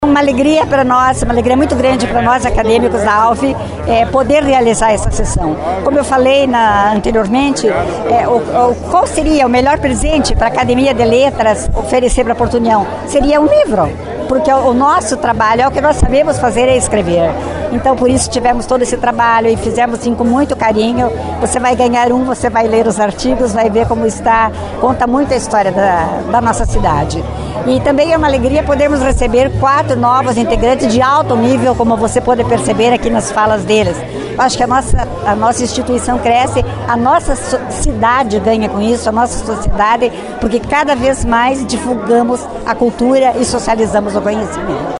A programação em homenagem ao município de Porto União, pelos seus 100 anos, teve continuidade na noite dessa sexta-feira, 22 de setembro, no salão nobre do Colégio Santos Anjos.